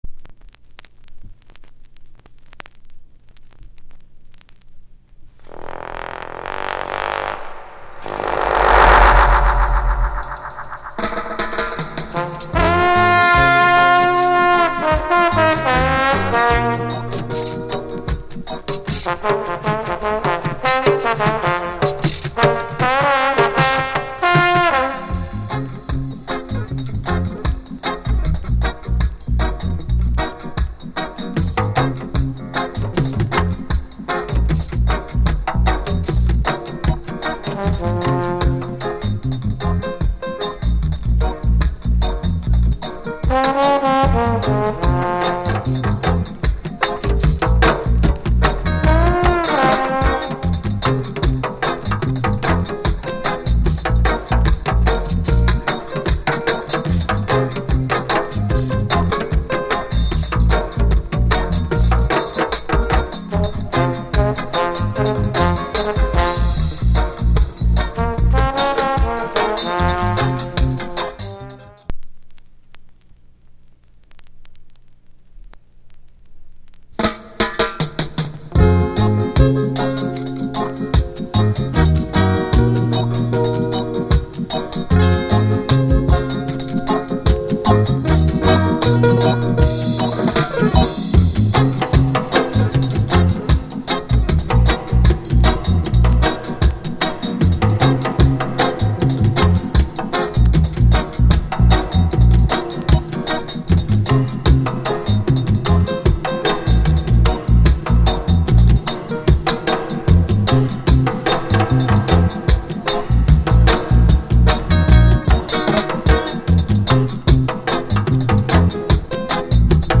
* Mega Rare Roots LP!!